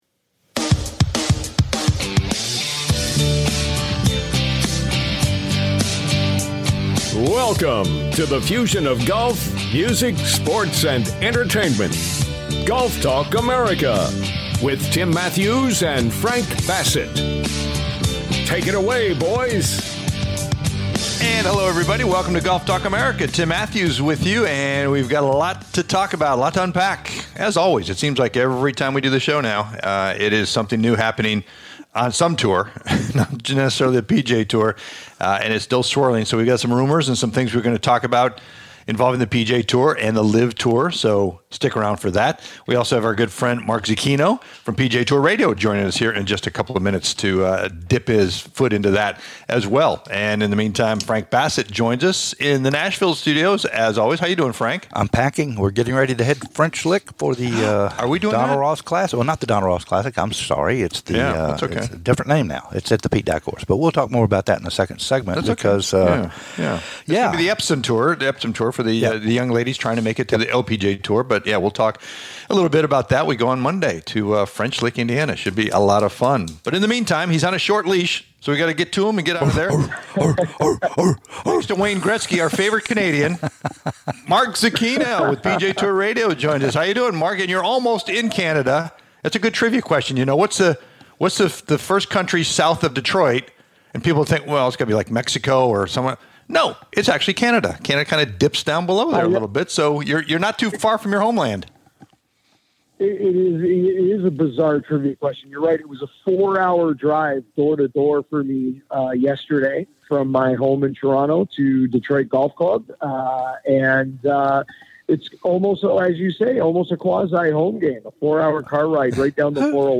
"LIVE" from The Rocket Mortgage Classic at Detroit Golf Club